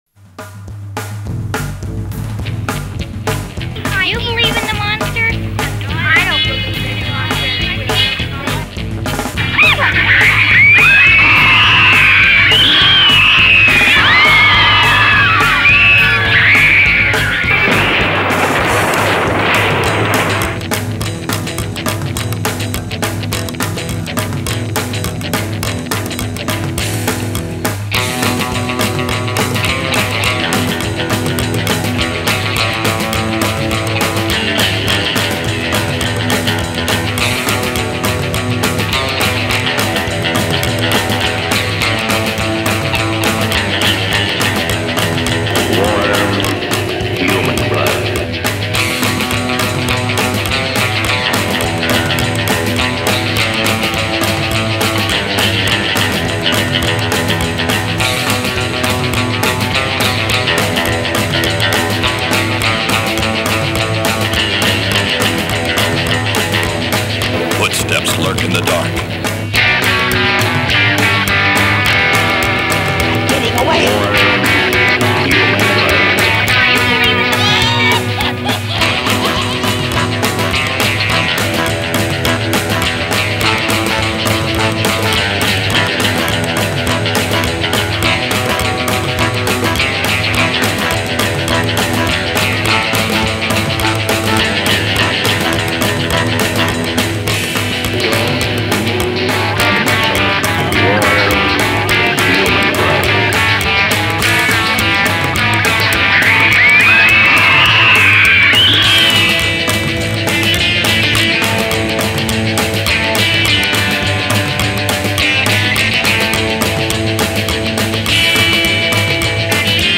Psycho rockers